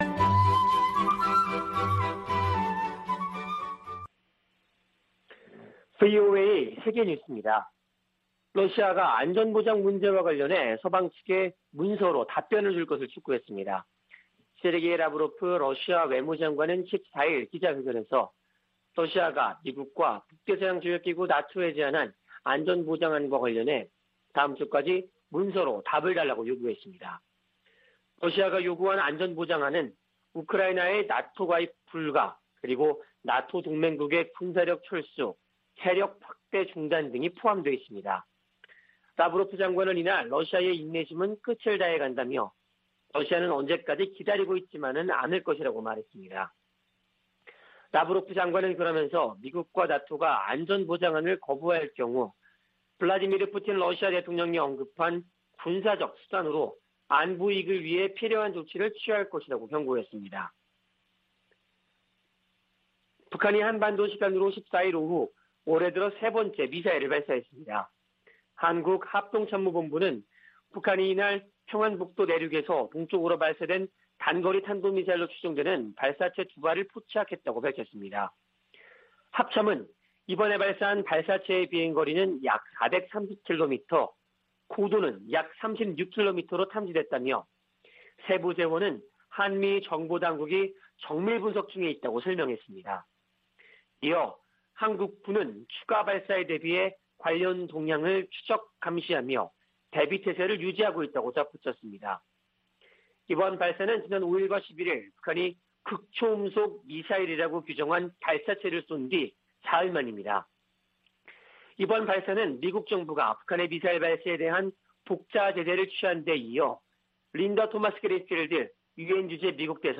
VOA 한국어 아침 뉴스 프로그램 '워싱턴 뉴스 광장' 2021년 1월 15일 방송입니다. 북한이 사흘 만에 또 단거리 탄도미사일로 추정되는 발사체 2발을 쐈습니다. 토니 블링컨 미 국무장관은 북한이 대화 제의에 미사일로 화답했다며, 책임을 물을 것이라고 강조했습니다. 북한의 미사일 부품 조달에 관여해 미국의 제재 명단에 오른 북한 국적자들이 유엔 안보리 제재 대상 후보로 지명됐습니다.